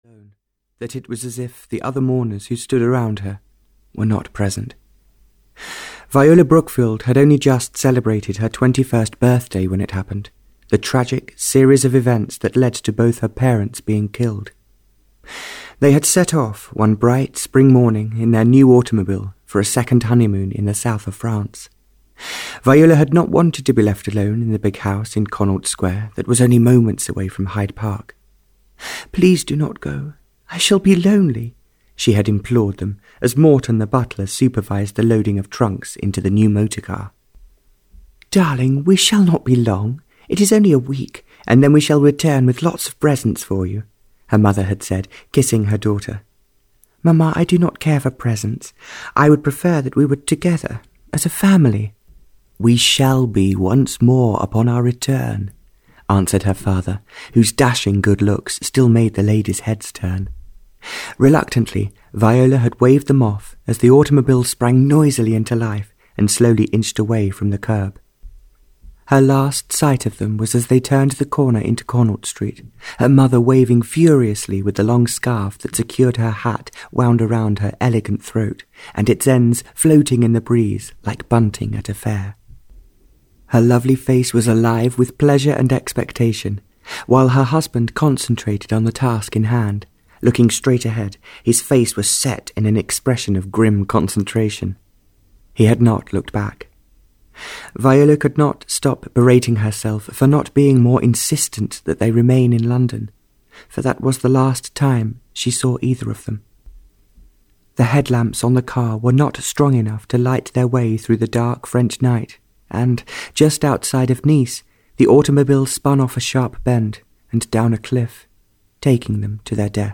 Audio knihaFor Ever and Ever (Barbara Cartland’s Pink Collection 32) (EN)
Ukázka z knihy